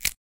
press_button.ogg